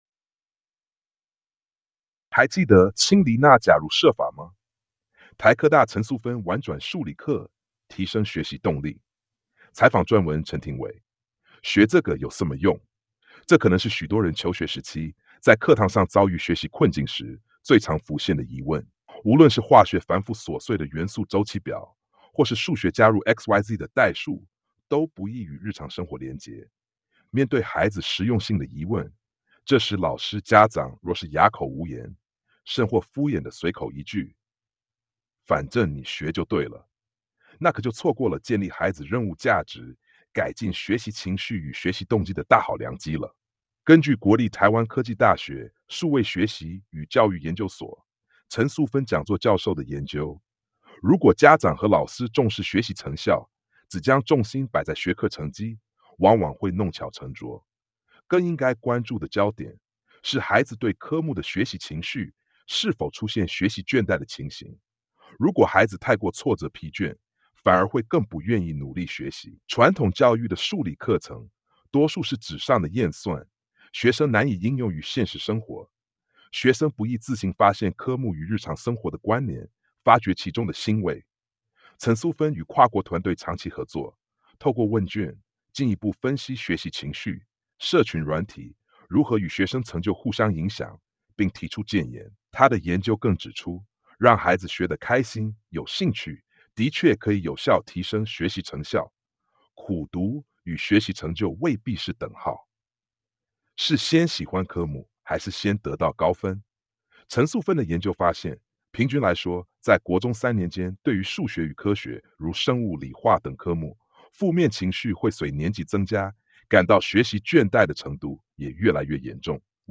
全文朗讀： 「學這個有甚麼用？」